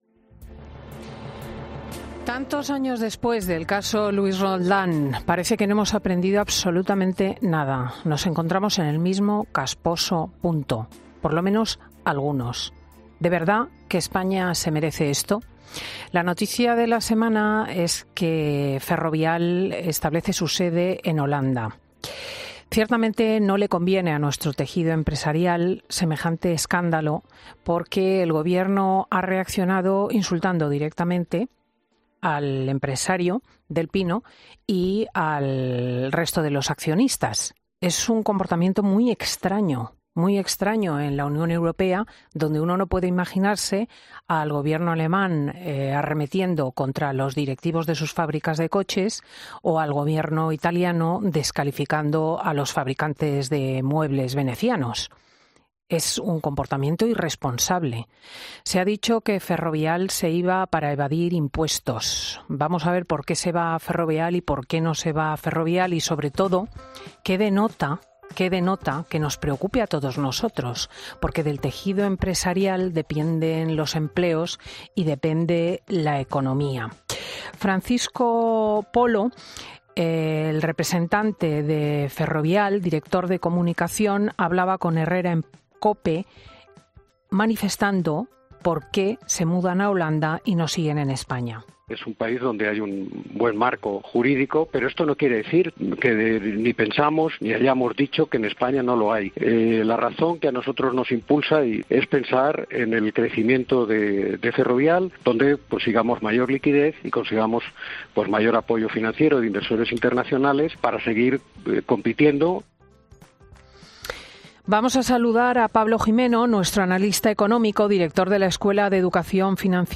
analista económico, ha pasado por los micrófonos de 'Fin de Semana' para explicar por qué Ferrovial prefiere salir de España